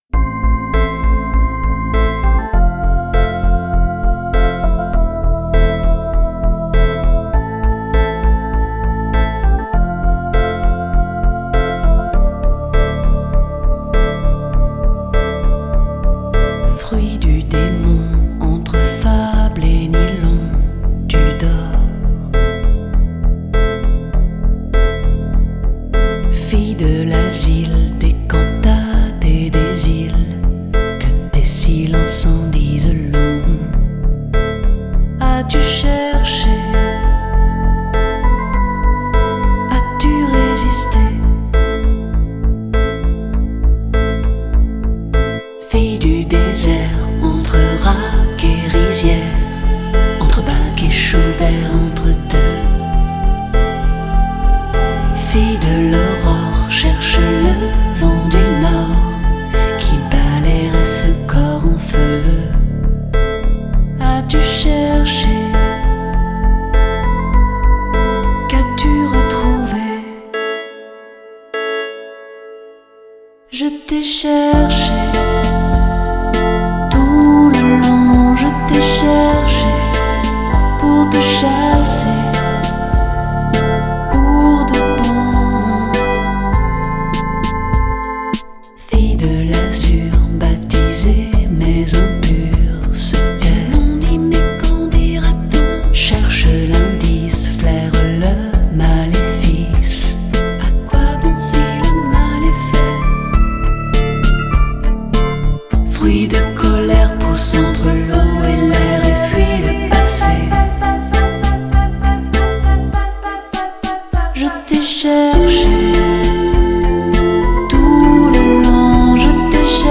Genre: French Pop, Chanson